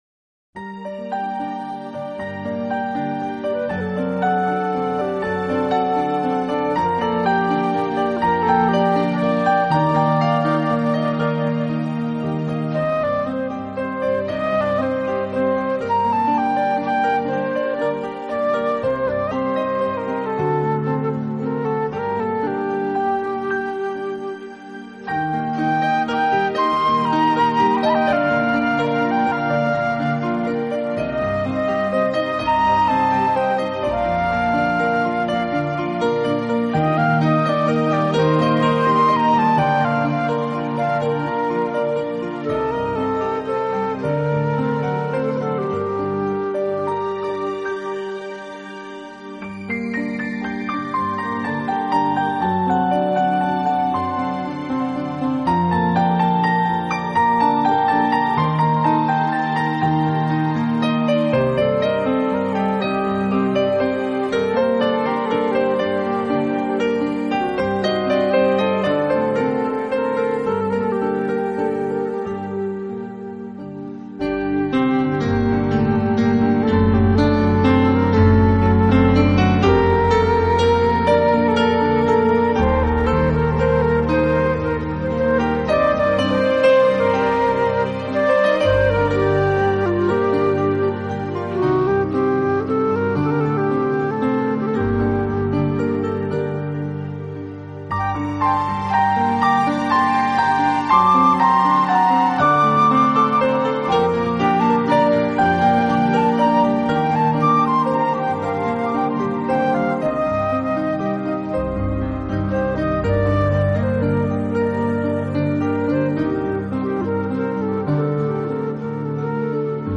两种乐器无主副，高低起伏，一承一接，电子琴键
轻脆可人，笛声连绵不尽，配合得天衣无缝。录音没有花巧，来得真而直接！